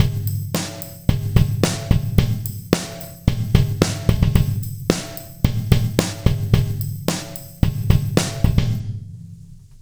For demonstration purposes, we’ve set up a basic groove featuring kick and snare as well as some tambourines.
And this is how the same drum loop sounds after some effect treatment:
In conjunction with the high DECAY value the drums will sound even bigger.
The snare now imparts a bright sound.
The tambourine is now very unobtrusive.